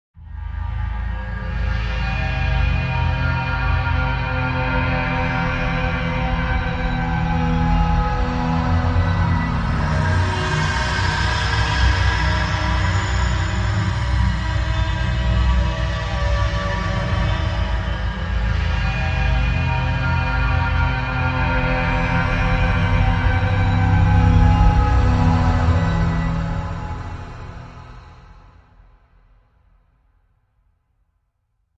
Scary Wind Drone Ambience
Description: Scary wind drone ambience. Creepy eerie scary ambient atmosphere. Dramatic suspense tension theme. Horror background sound effect.
Genres: Sound Effects
Scary-wind-drone-ambience.mp3